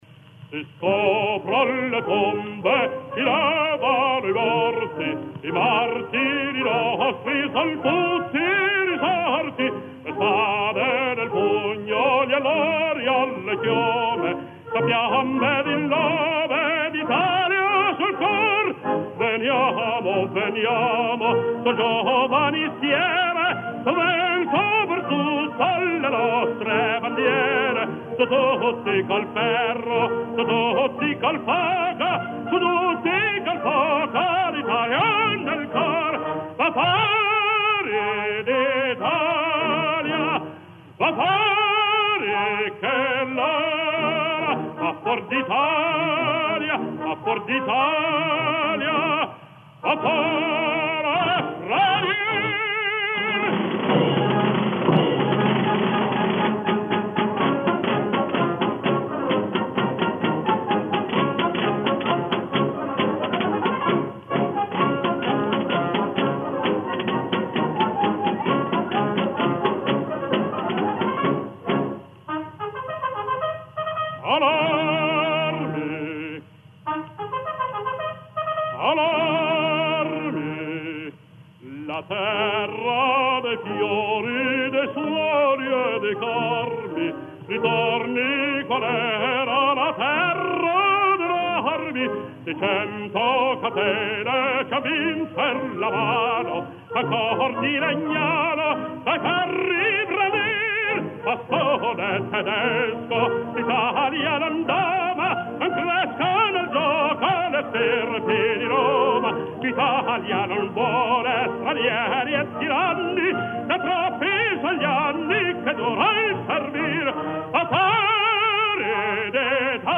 CANTI DEL RISORGIMENTO
Inno di Garibaldi cantato da Enrico Caruso (qui il testo)